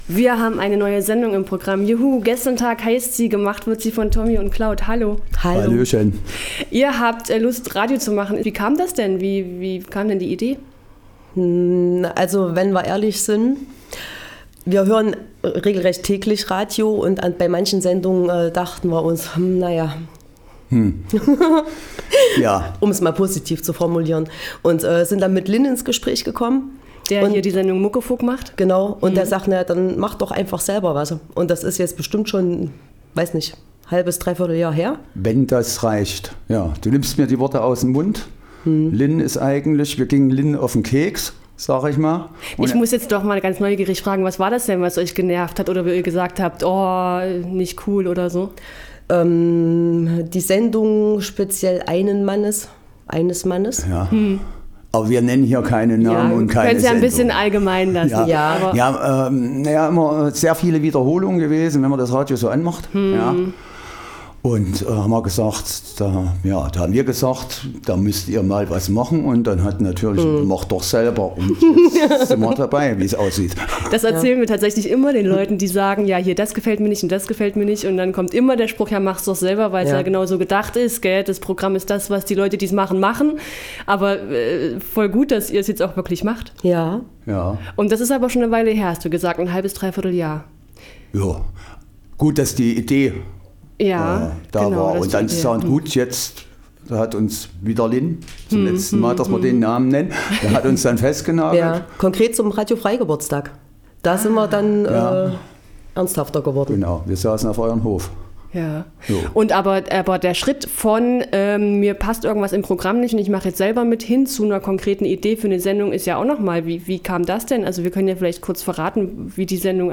Im Gespräch erzählen sie, wie es kam und was sie da in der Sendung eigentlich machen.